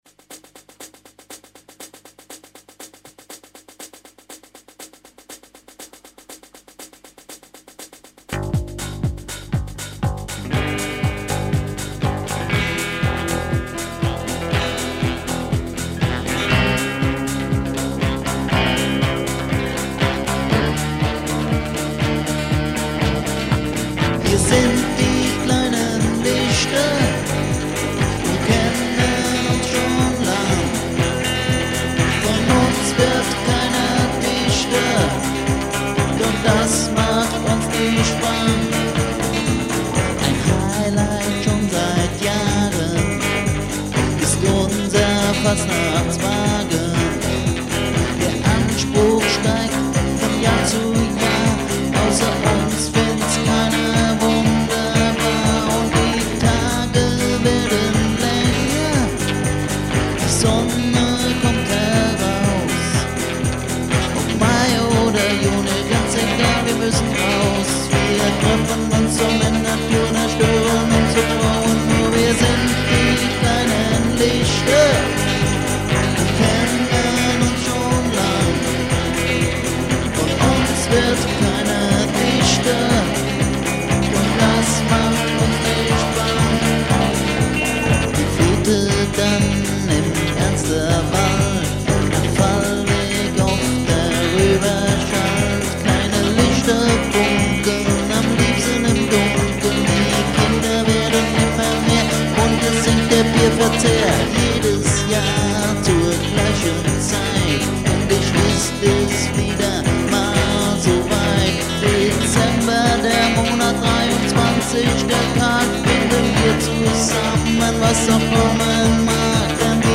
Recorded at the Roof-Top-Chamber, Bad Ems 16.12.2000